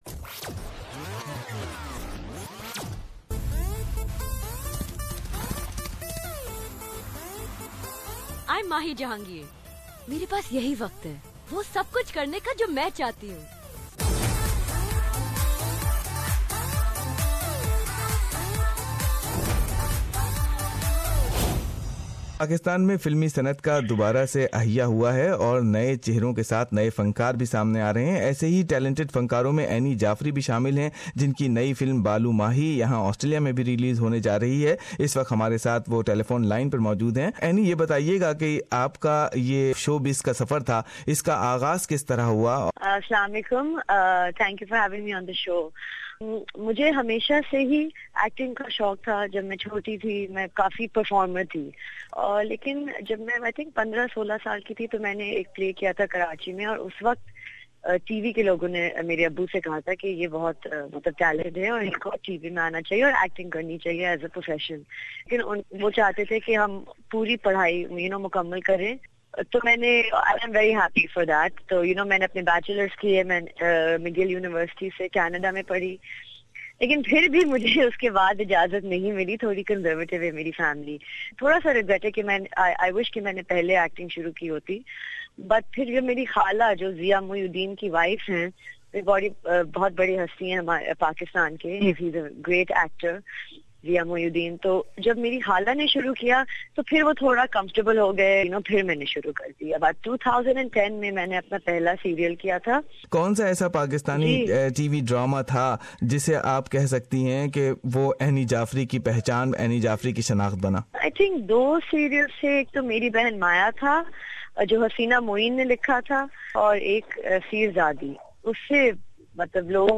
Despite all her success she has one regret? Listen details in her interview